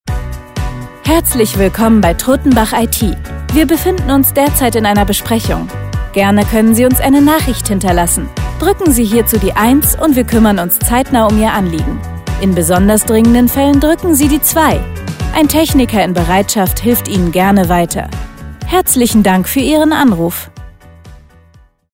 IT Telefonansage: Besprechung